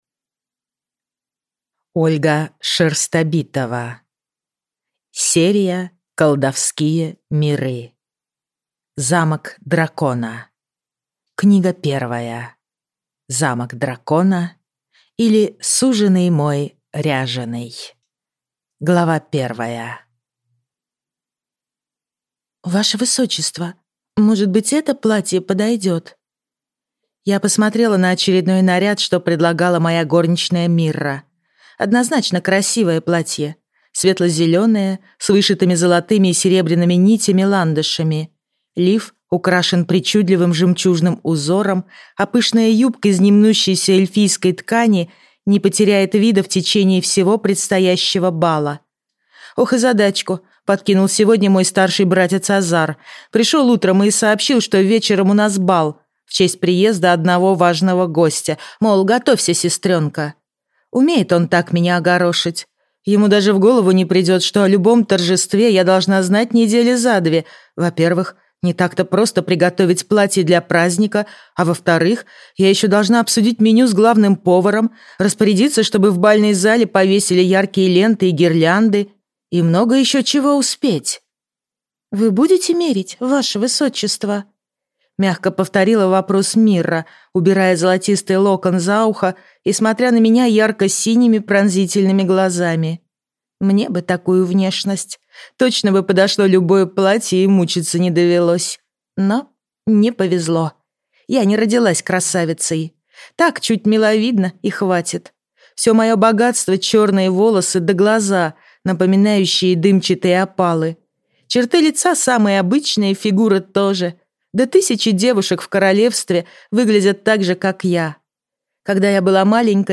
Аудиокнига Замок дракона, или Суженый мой, ряженый | Библиотека аудиокниг